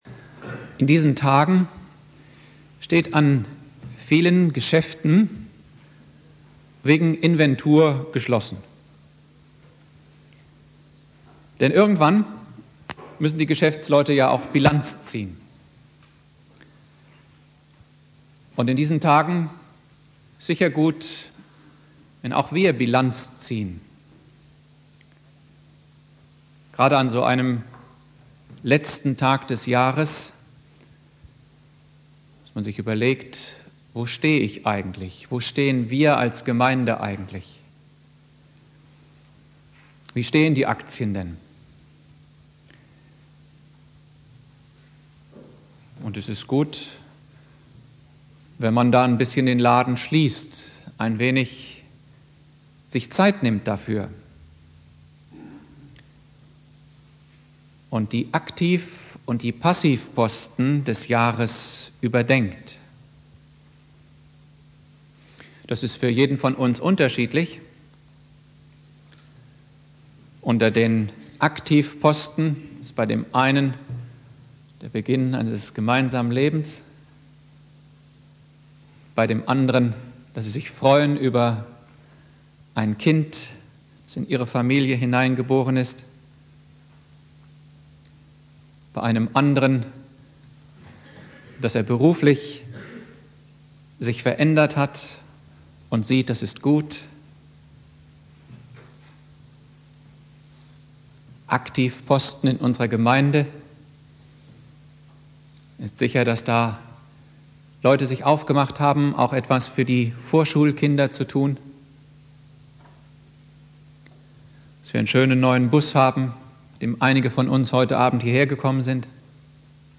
Predigt
Silvester